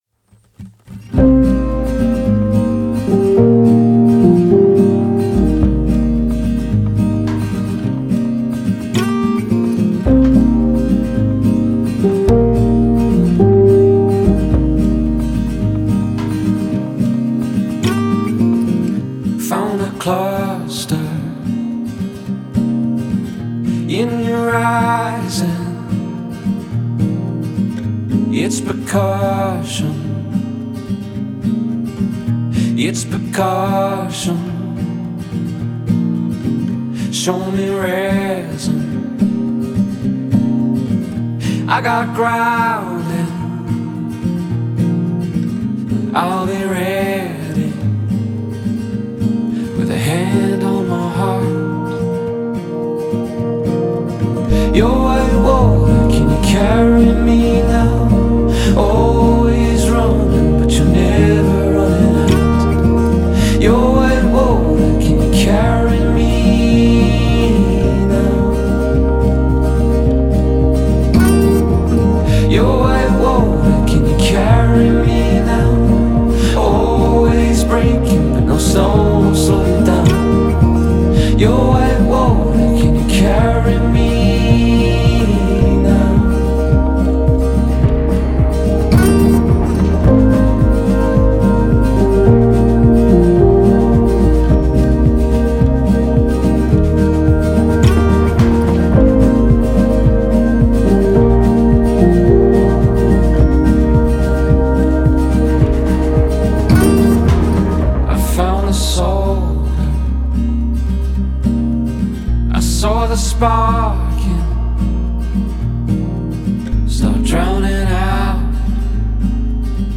Alternative/Indie Indie Folk